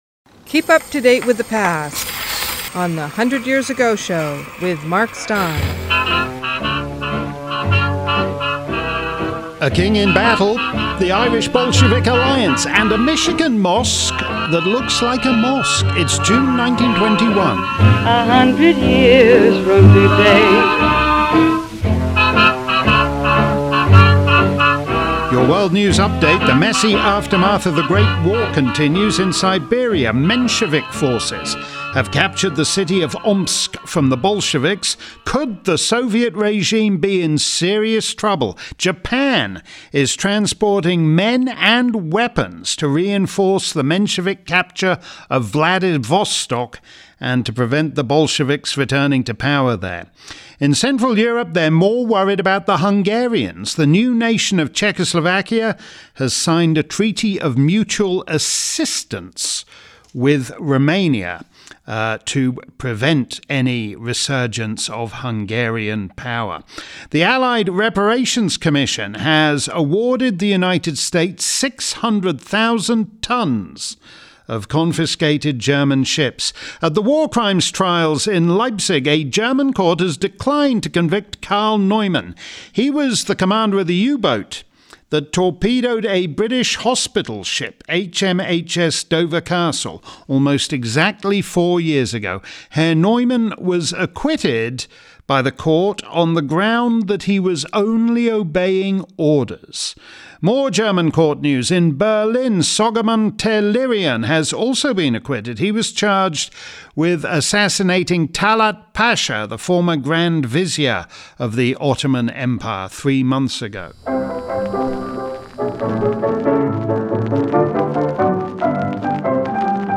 The Hundred Years Ago Show began as a weekly feature of The Mark Steyn Show with Mark's "world news update" accompanied by some appropriate musical sounds from a century ago.